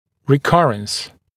[rɪ’kʌrəns][ри’карэнс]рецидив, повторное проявление